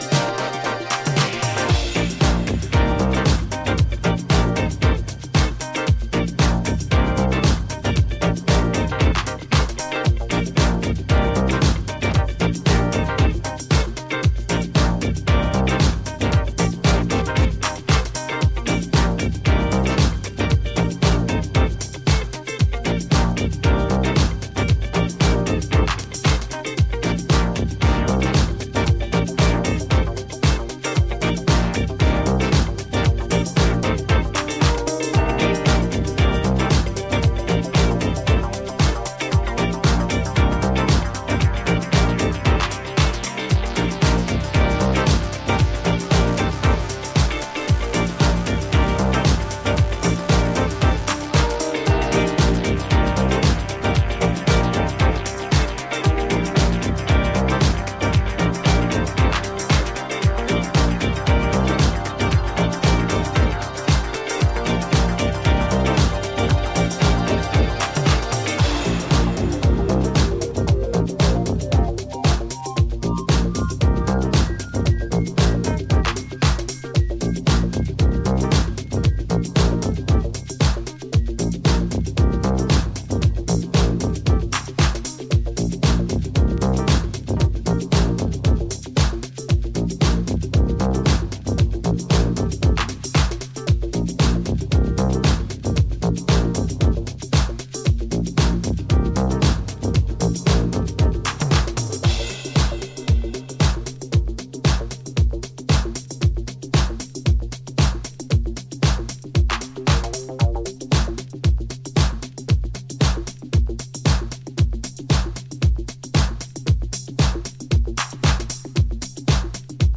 ジャンル(スタイル) NU DISCO / DEEP HOUSE